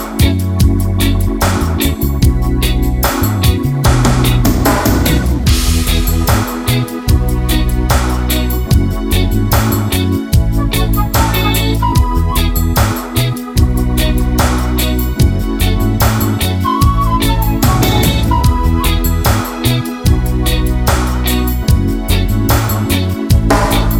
no Backing Vocals Reggae 4:20 Buy £1.50